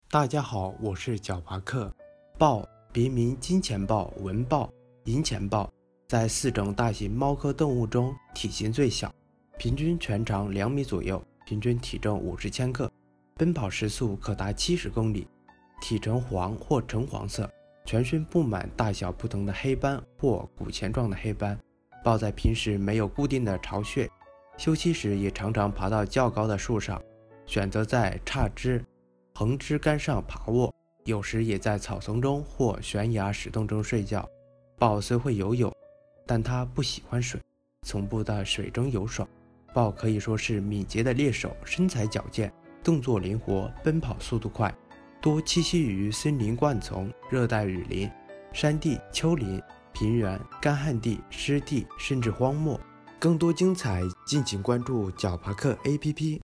豹-----呼呼呼～～～